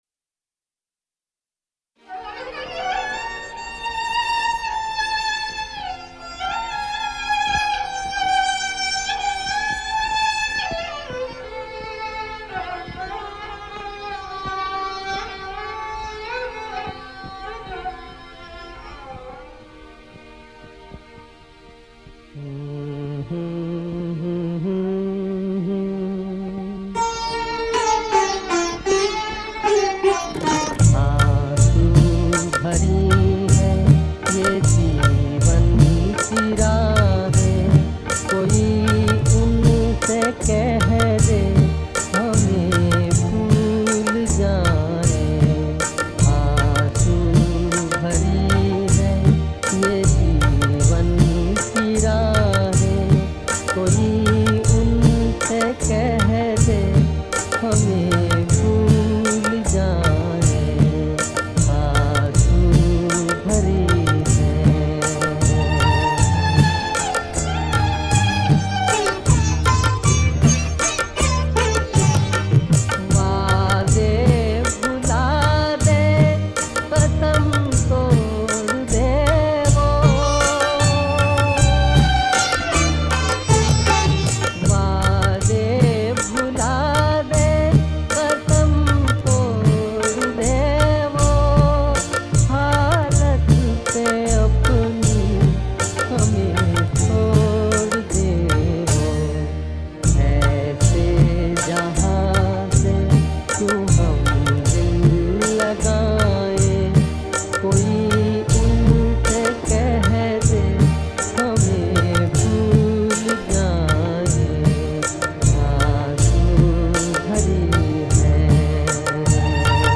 Karaoke Version Video Lyrics Track